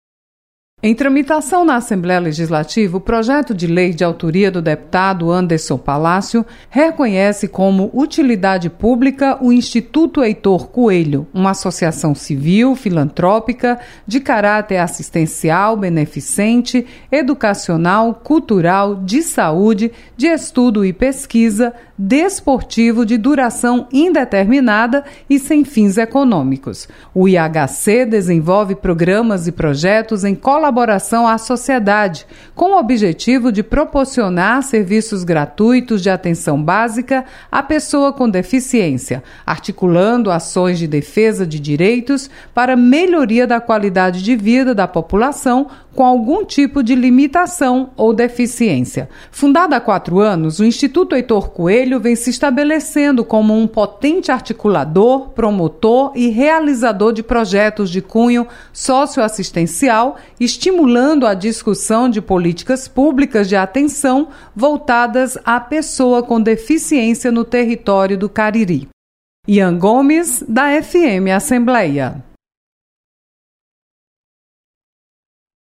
Projeto decreta como Utilidade Pública o Instituto Heitor Coelho. Repórter